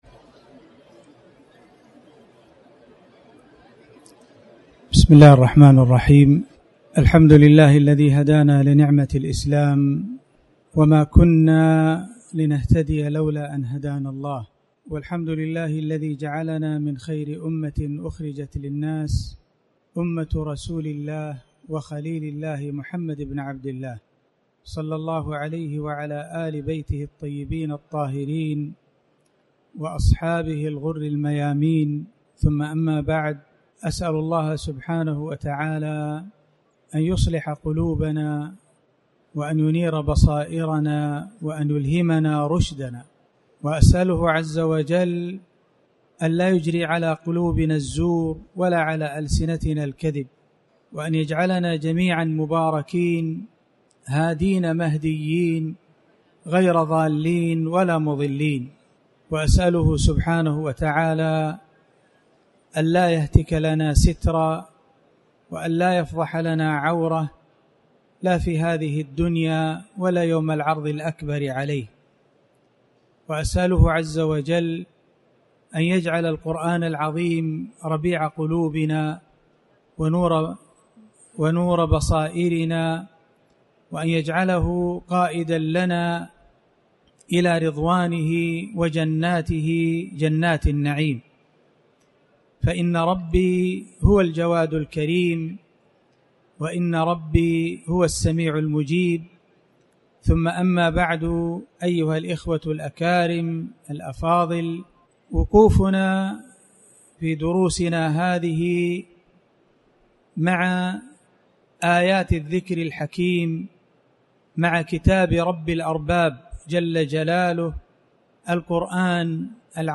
تاريخ النشر ١٠ ذو القعدة ١٤٣٩ هـ المكان: المسجد الحرام الشيخ